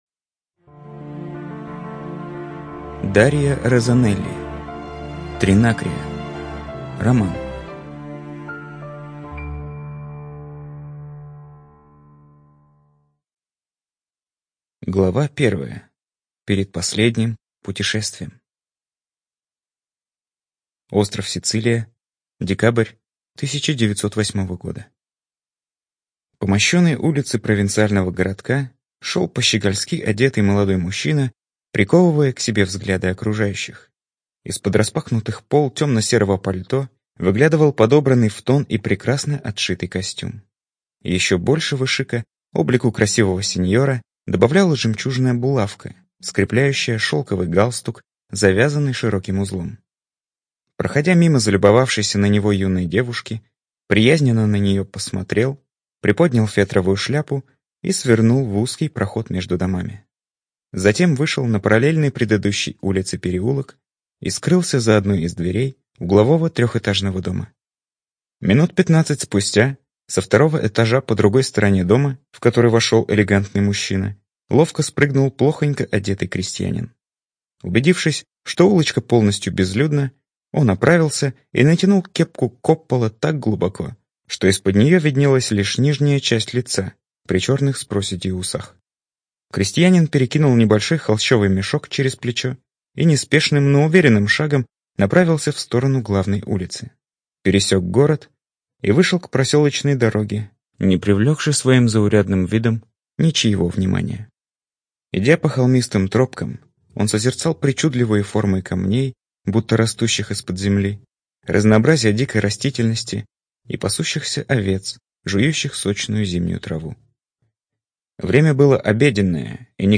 ЖанрПриключения